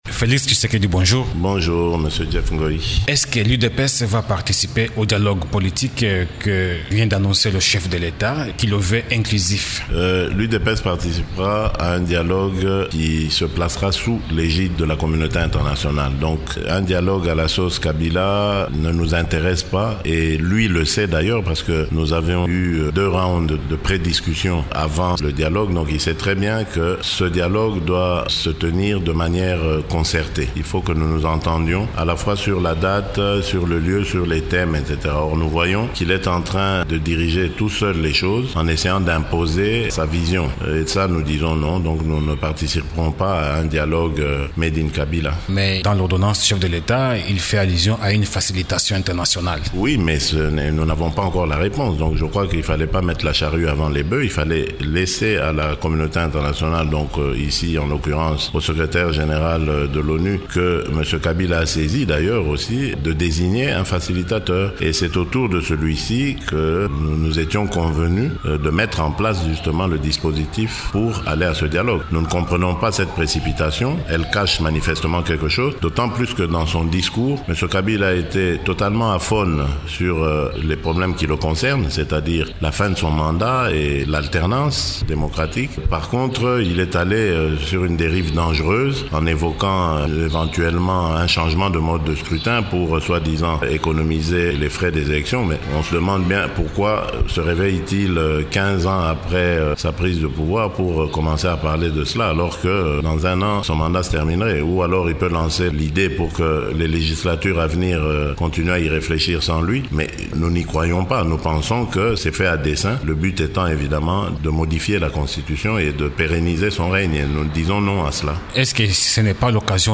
Le secrétaire national de l’UDPS en charge des affaires extérieures, Félix Tshisekedi, est l’invité de Radio Okapi ce mercredi.